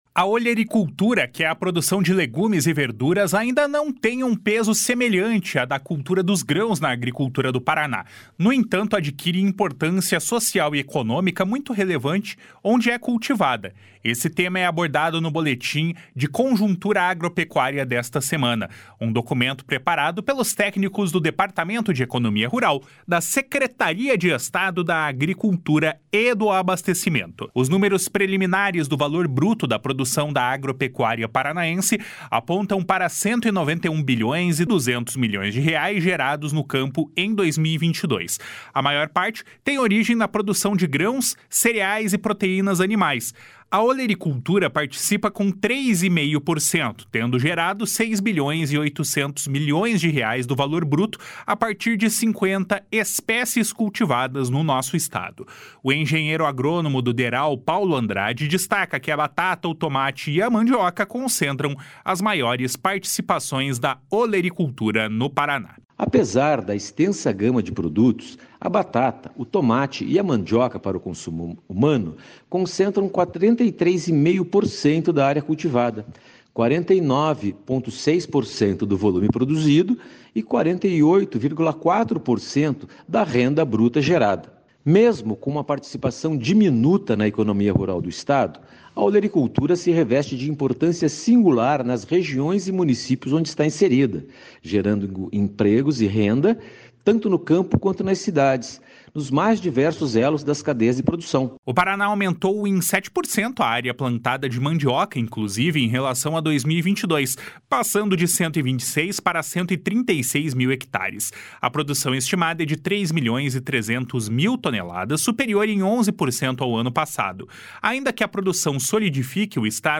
OLERICULTURA - BOLETIM DERAL.mp3